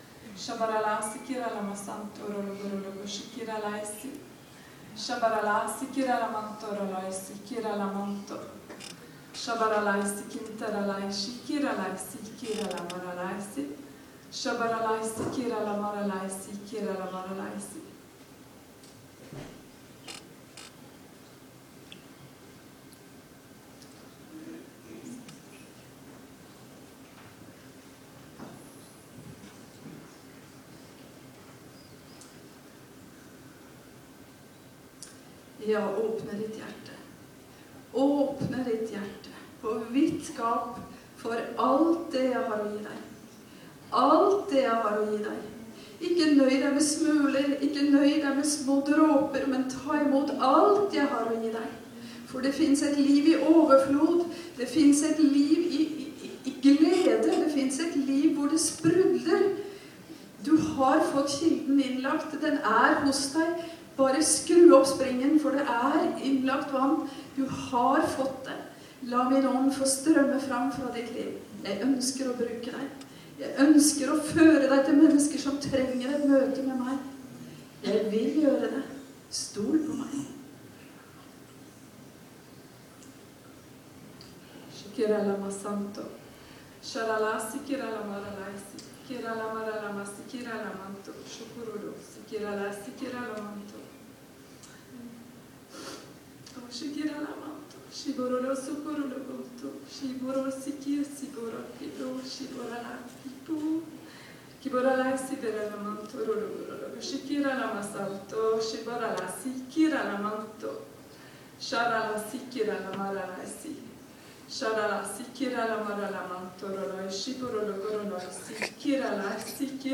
TYDING AV TUNGETALE PÅ KVELDSMØTET: